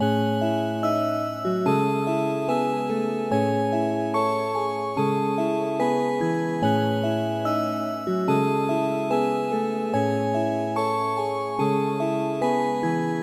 大都会博明和弦和旋律
描述：有一点悲伤，有一点阴森。
Tag: 145 bpm Trap Loops Synth Loops 2.23 MB wav Key : A